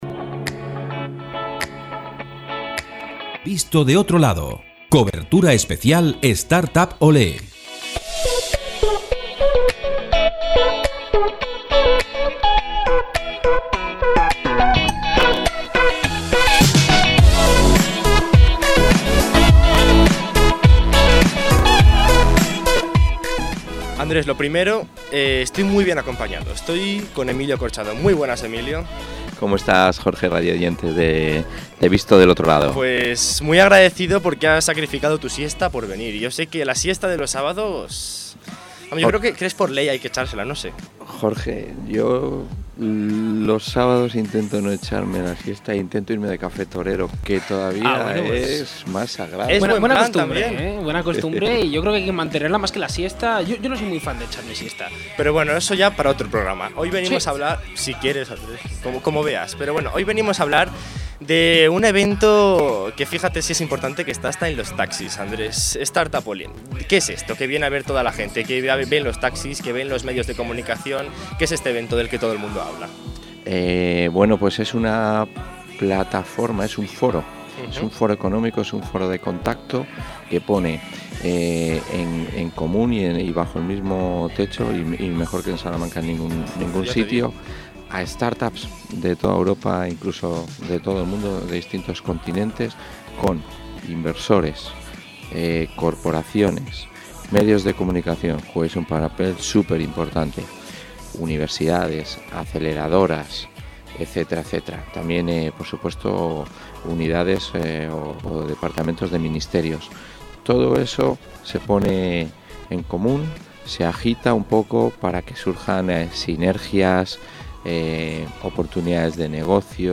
Podcast: Entrevista